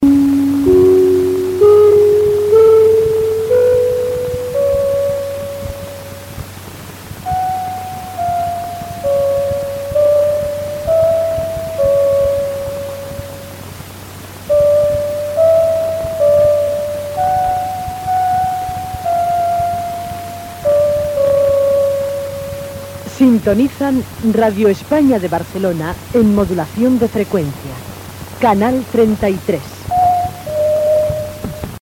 Sintonia de l'emissora, identificació.